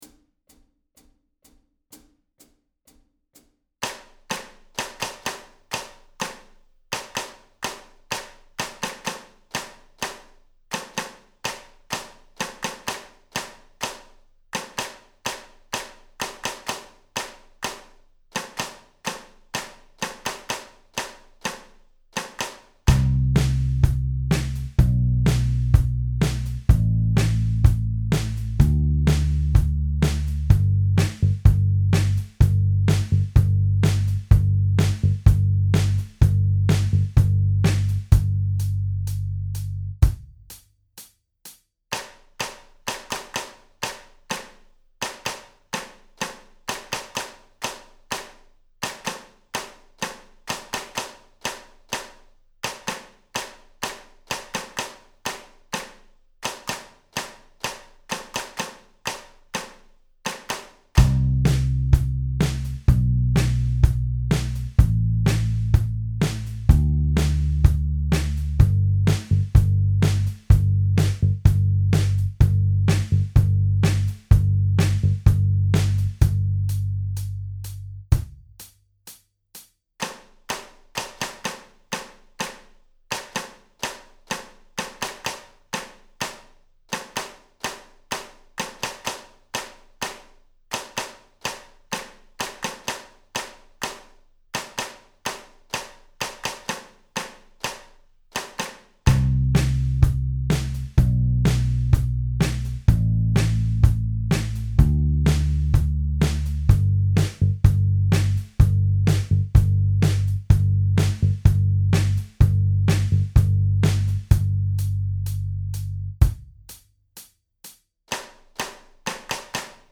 Audio Practice Tracks
Each practice track has a 2 measure count-in, and then the clapping percussion begins.
Moderate Tempo (126bpm) - download, or press the play button below to stream: